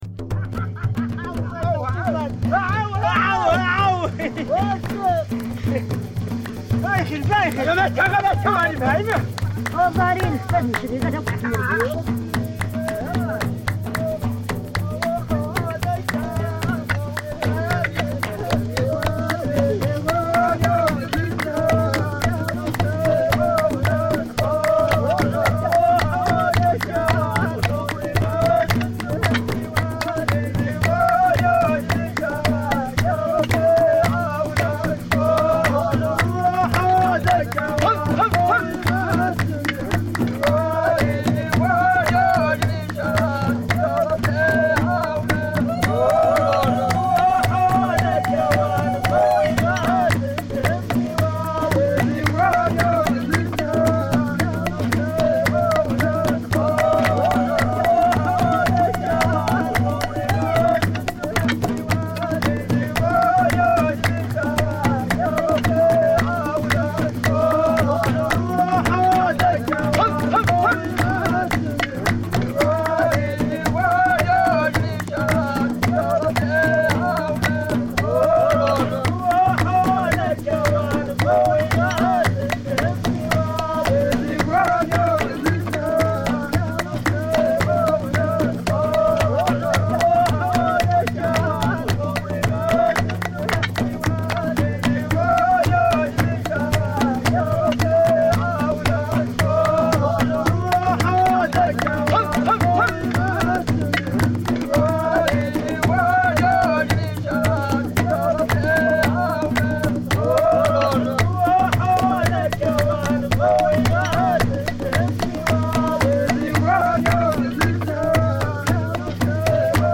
I tried to stay true to the recording, at the same time reimagining the setting. It is a threshing song; I changed the setting to be more geared towards sitting around a fire and singing; or a wedding.
Even in the mix, I opted for the percussion instruments to be overpowering as it is usually the case in such settings. My idea was not to manipulate the recording as much as to re-adapt it; picking up a part that makes sense rhythmically and recording several percussion instruments.